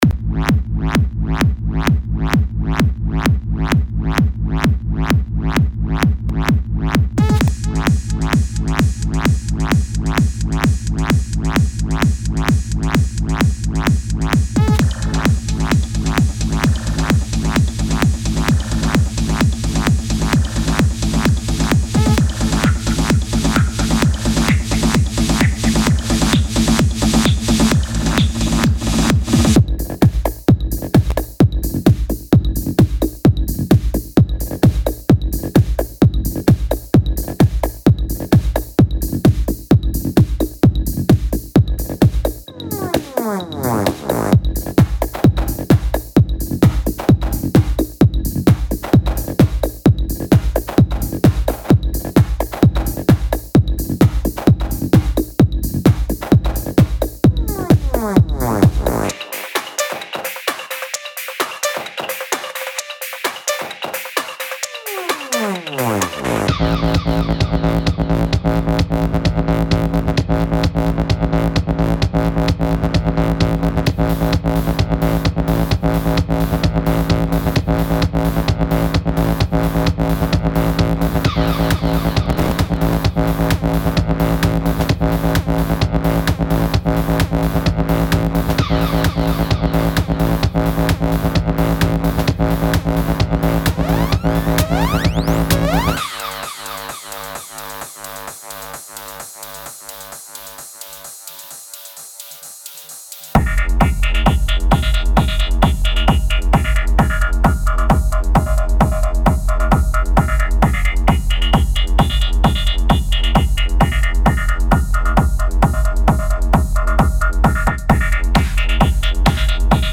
machine basslines,synthlines and SFXs.